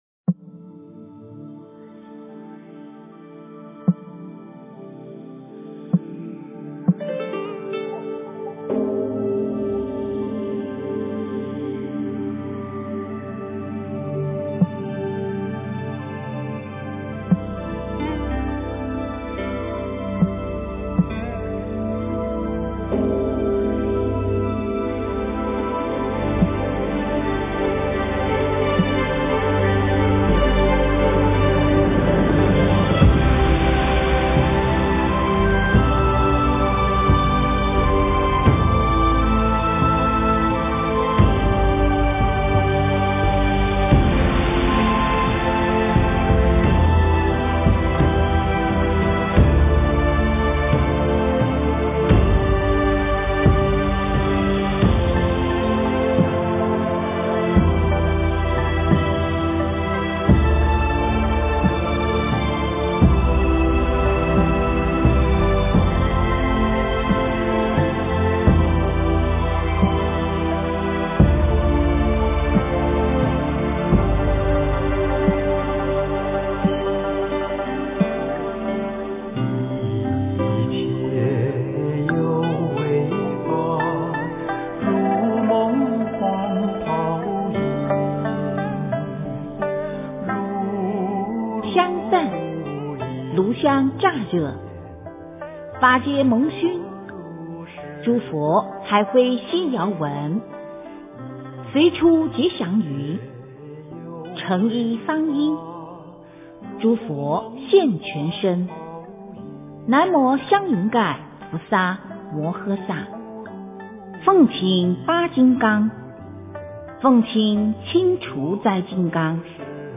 金刚经 - 诵经 - 云佛论坛